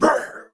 client / bin / pack / Sound / sound / monster / skeleton_general / damage_2.wav
damage_2.wav